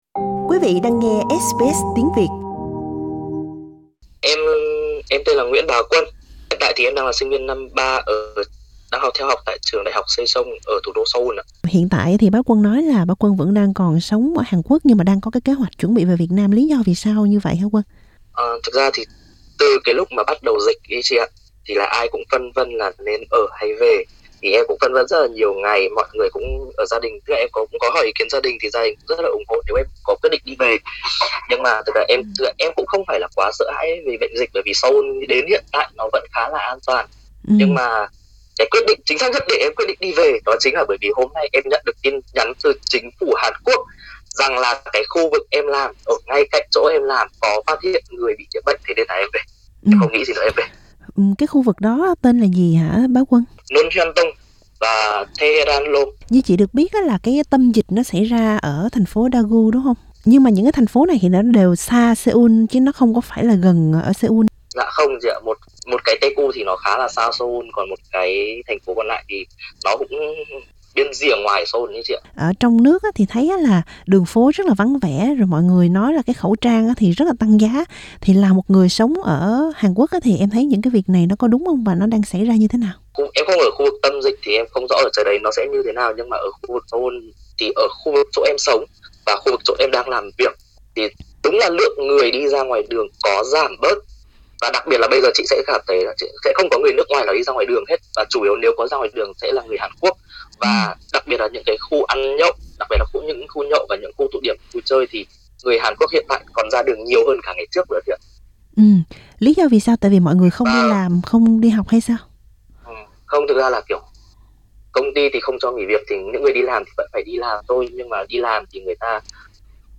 Nhiều người Việt sống tại Hàn đang "tháo chạy" về nước, bất chấp việc bị chính phủ Việt Nam cách ly, trong khi người dân Seoul vẫn không hề lo lắng. Hai người Việt sống tại Hàn Quốc tường thuật với SBS tình hình tại đây.